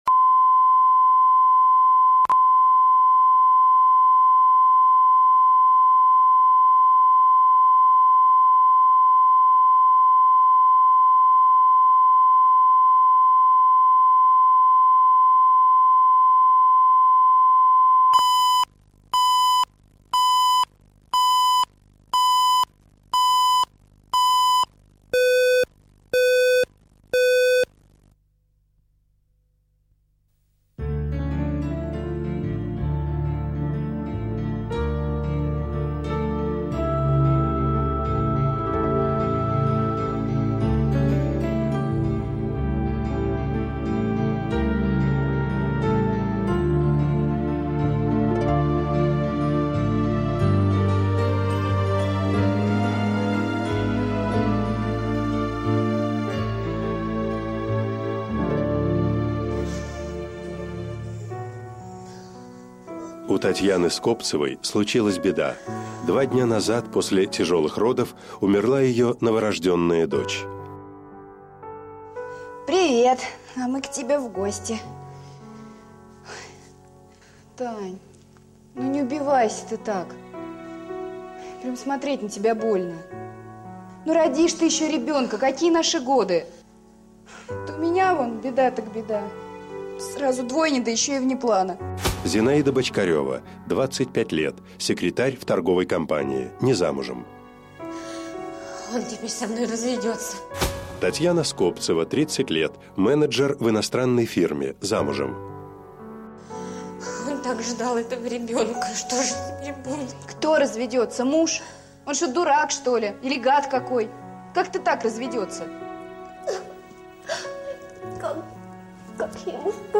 Аудиокнига Сделка | Библиотека аудиокниг